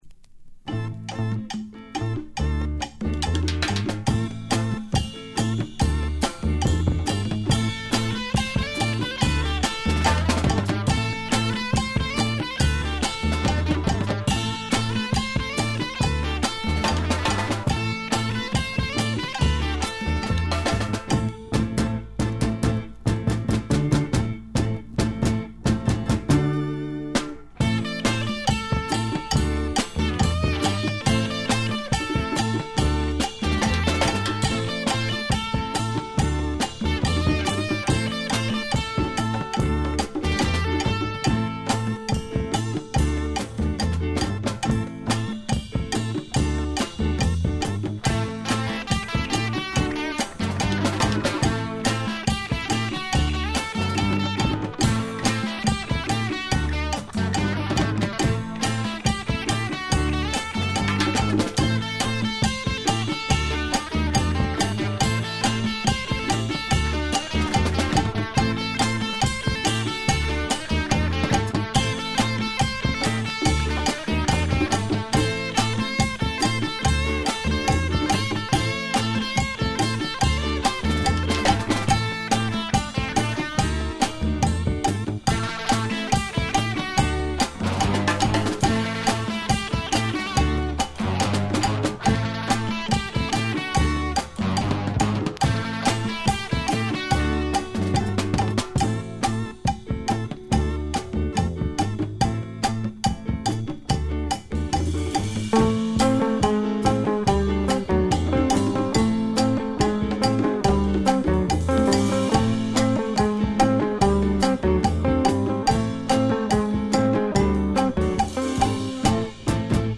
Published June 19, 2009 Garage/Rock , Queso Comments
I like the sloppy drum style on this one.